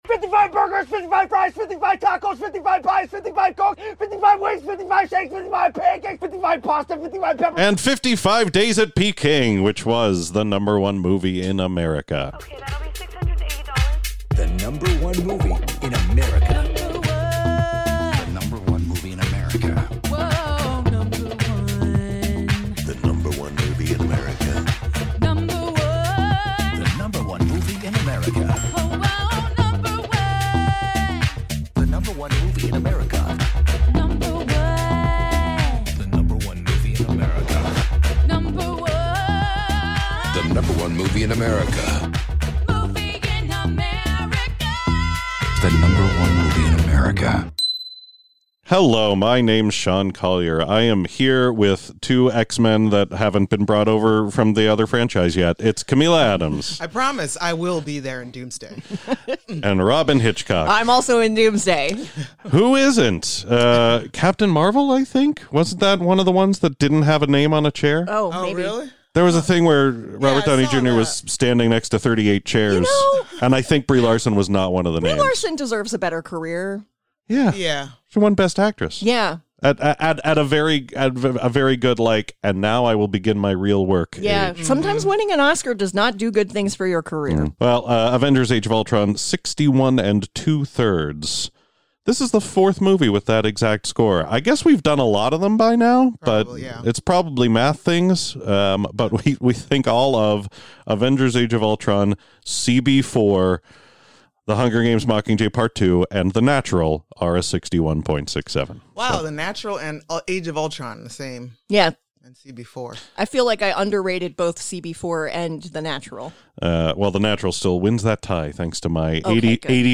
It is recorded at the iHeartMedia Podcast Studio in Pittsburgh.
Contains Adult Language